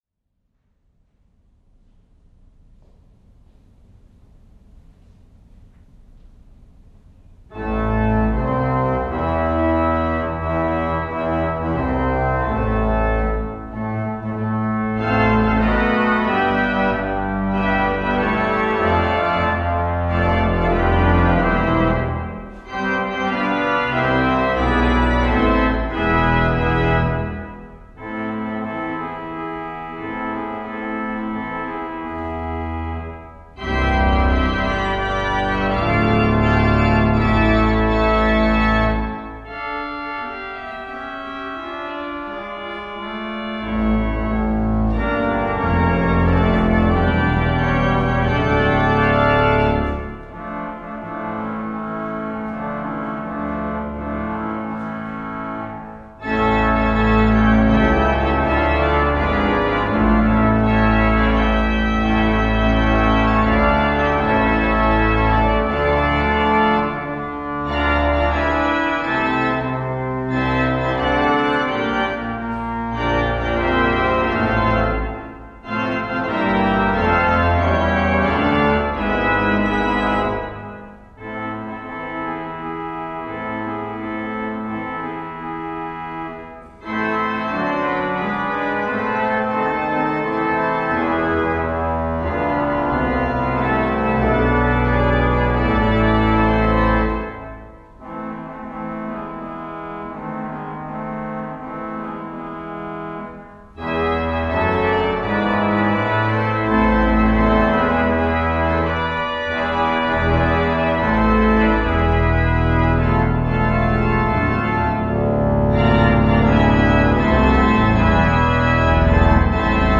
Wednesday Concert 3/19/14